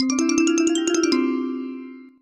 gameover.mp3